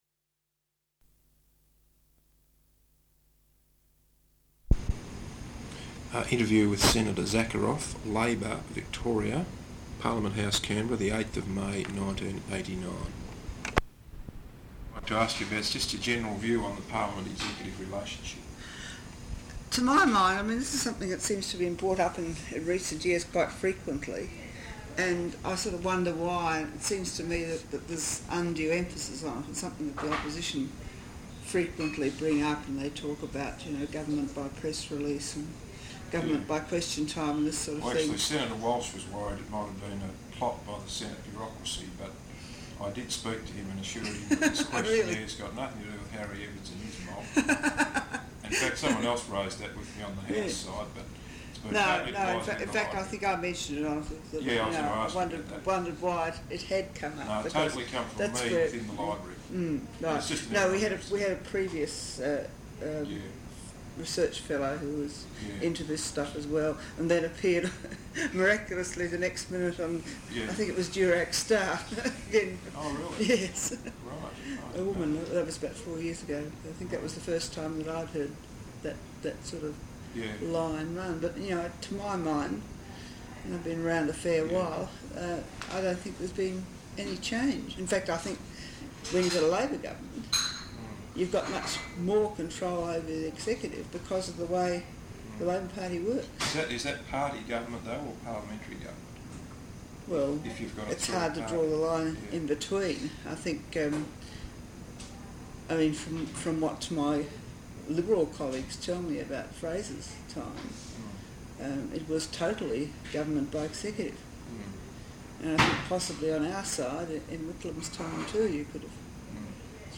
Interview with Senator Olive Zakharov, Labor, Victoria, at Parliament House, Canberra, 8 May, 1989.